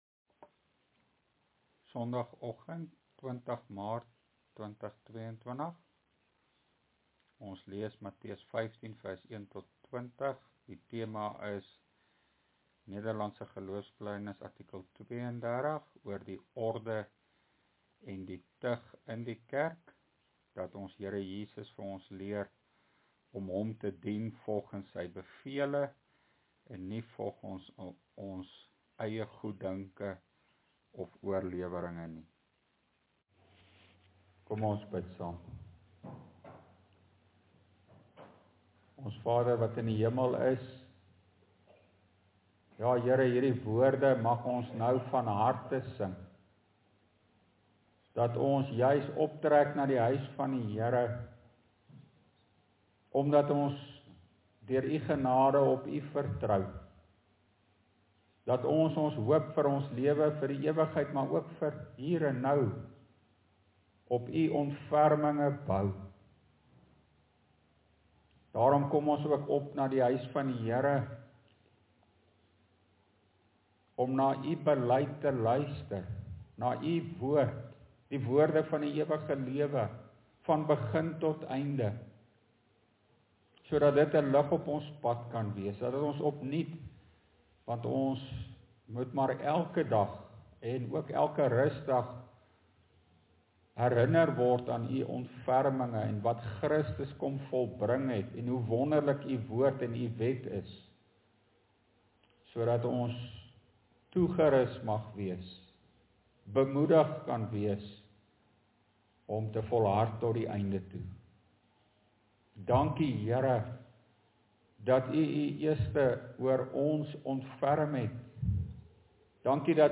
LEER PREDIKING: NGB artikel 32 – Die orde en tug van die kerk